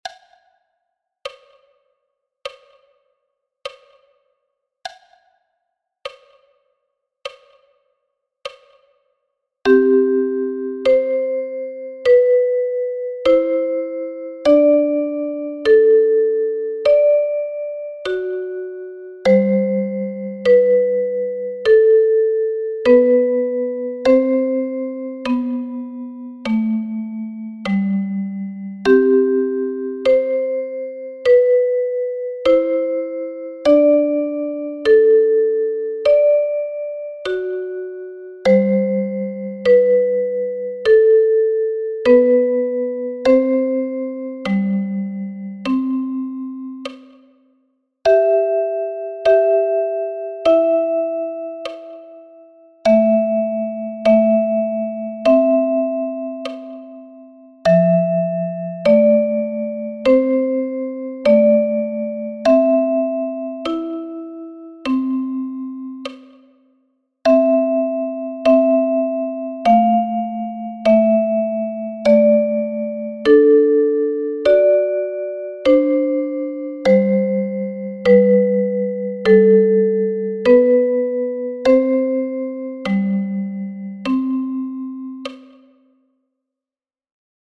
12 Evergreens – Duette für Sopran- & Altblockflöte